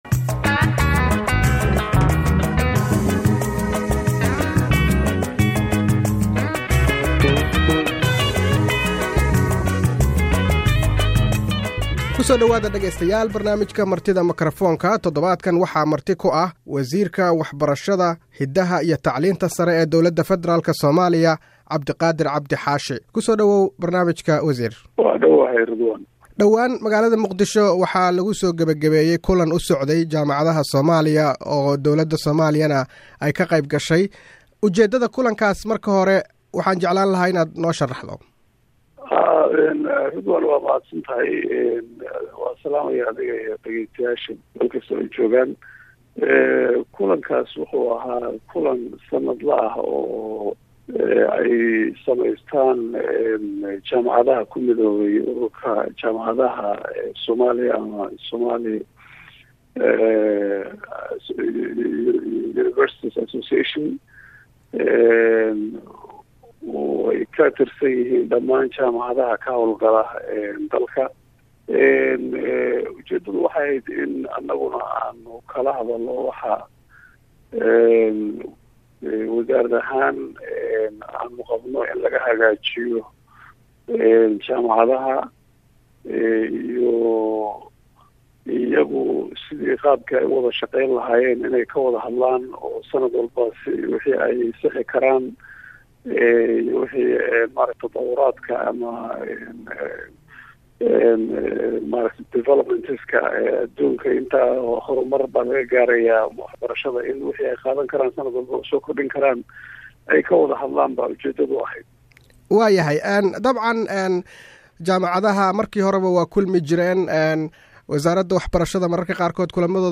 Barnaamijka Martida Makrafoonka waxa marti ku ah wasiirka waxbarashada Dowladda Federaalka ah ee Soomaaliya Md. Cabdiqaadir Cabdi Xaashi.